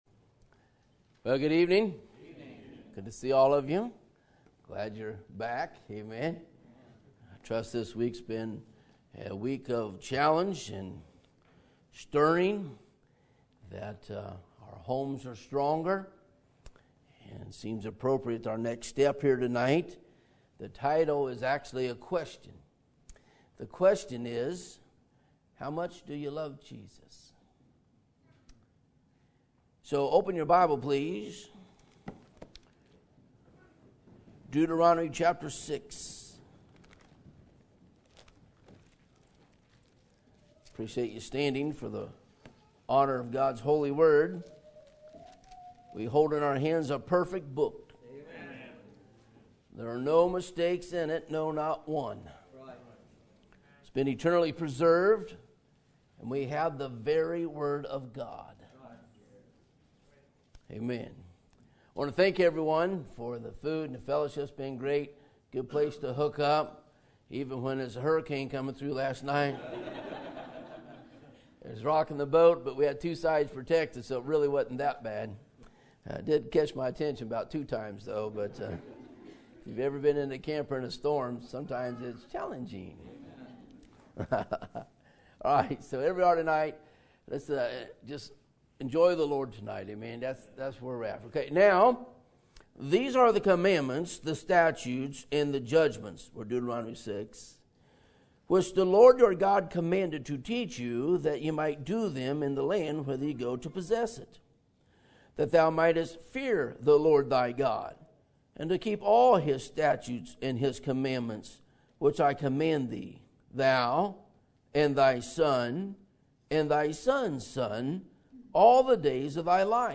Sermon Date: Thursday, June 4, 2020 - 7:00pm Sermon Title: How Much Do You Love Jesus?